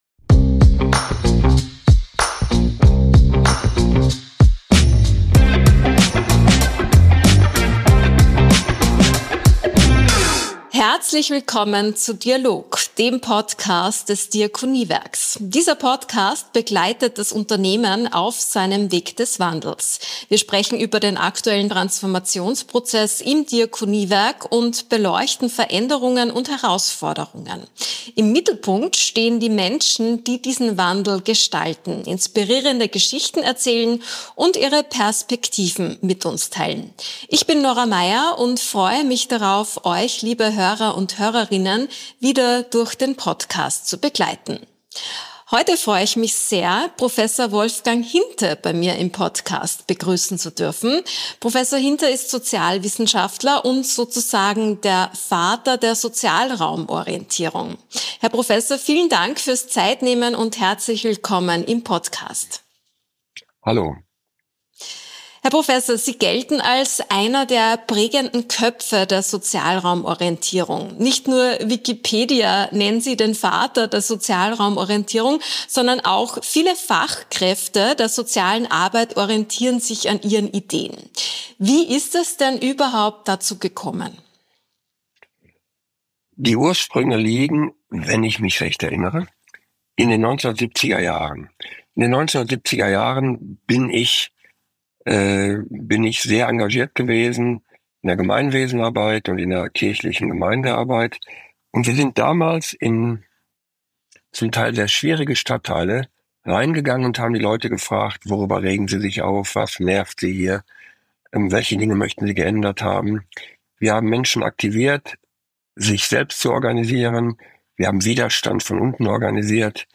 19_Wie gelingt Sozialraumorientierung? Im Gespräch